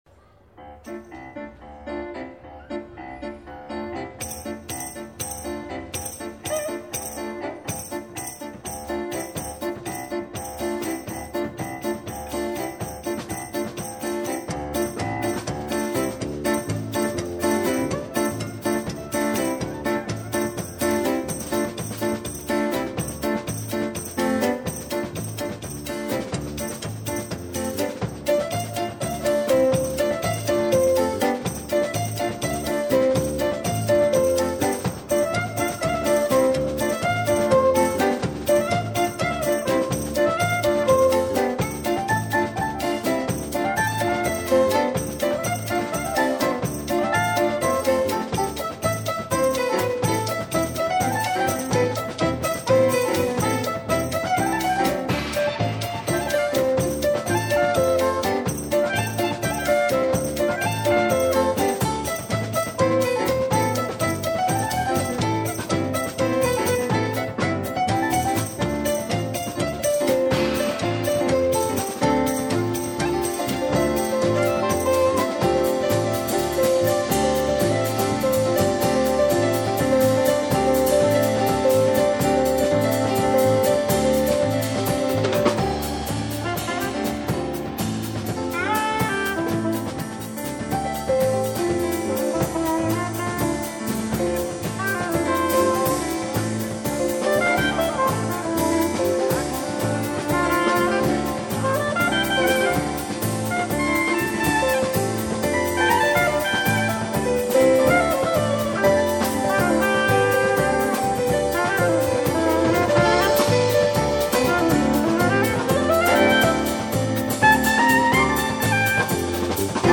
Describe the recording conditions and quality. Live auf dem Bundesplatz This is a live recording from September 9th 1989; we played at the Bundesplatz in Zug on a sunny Sathurday, surrounded by people with shopping bags.